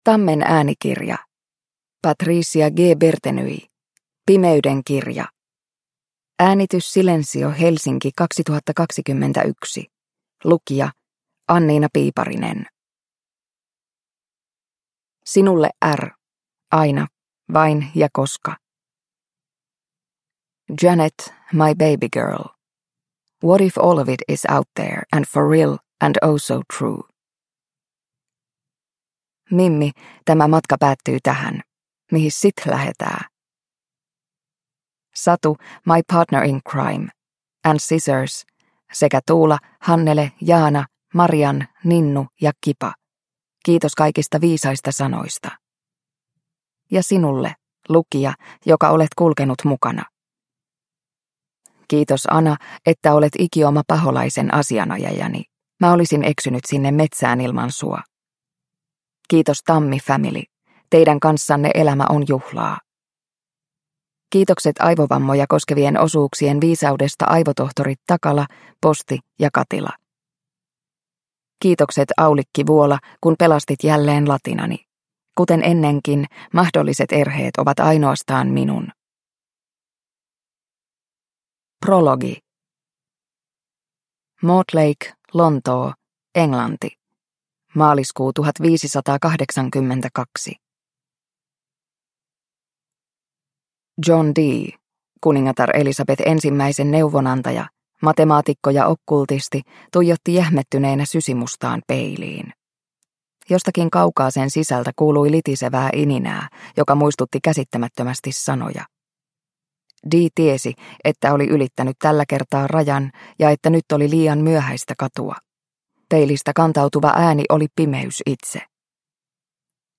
Pimeyden kirja – Ljudbok – Laddas ner